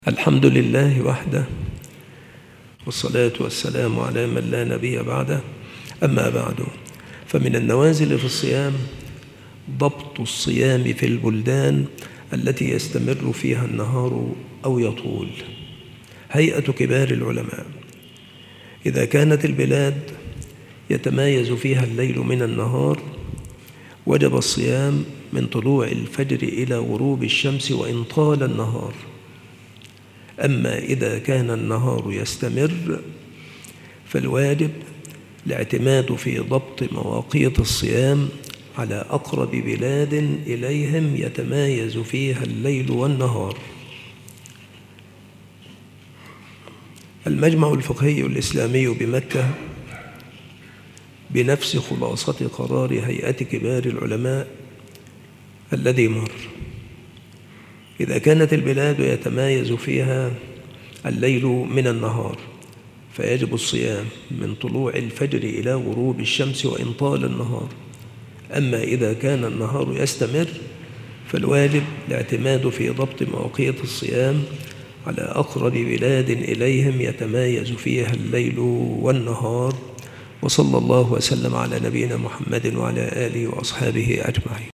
السلسلة مواعظ وتذكير
مكان إلقاء هذه المحاضرة بالمسجد الشرقي - سبك الأحد - أشمون - محافظة المنوفية - مصر